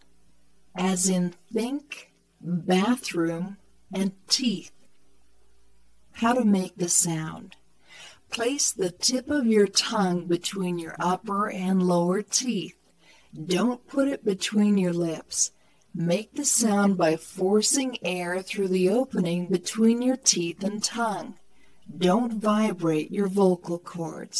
後で紹介するフリーソフトでWaveファイルに変換し、さらに別のフリーソフトで減速させました。
あくまでもフリーソフトなので音質はあまりよくありませんが、聞き取りには十分でしょう。